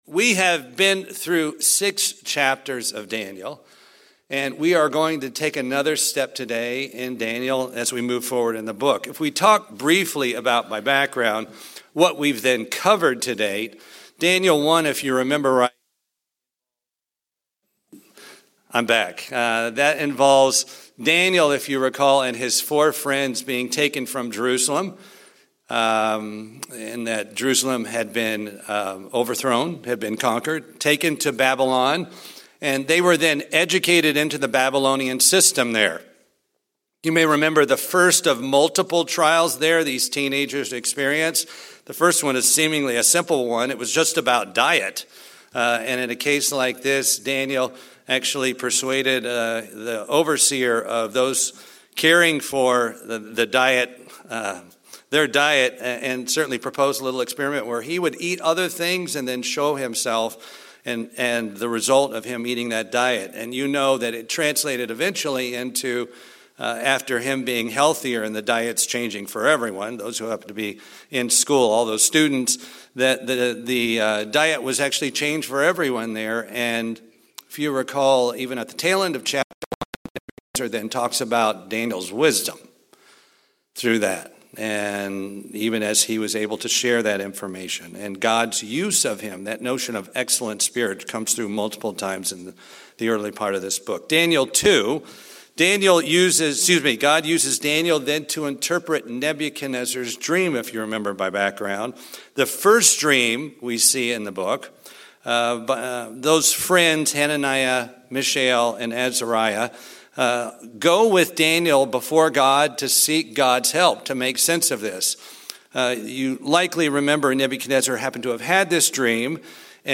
In a continuing series of sermons on the Book of Daniel, Chapter 7 is discussed.